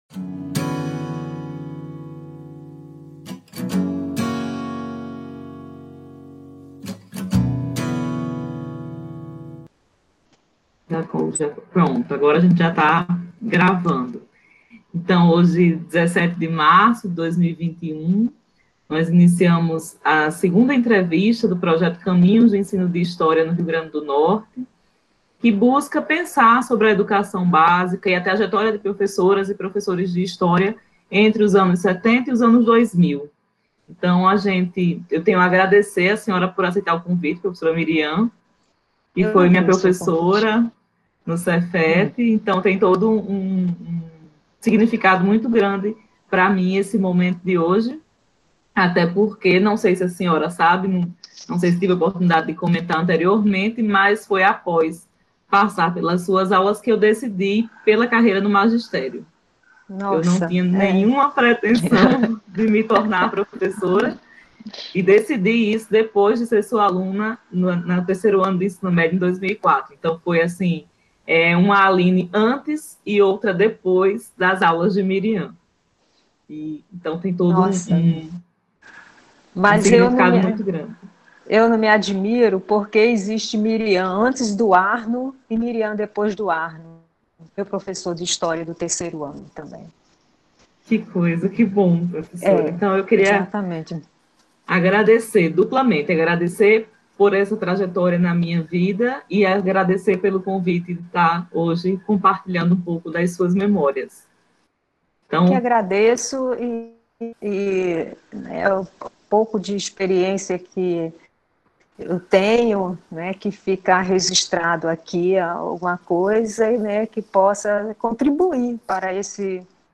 Projeto: Rede Trajetórias Docentes Tipo: história de vida Local: Natal/Rio de Janeiro - remota